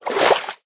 swim2.ogg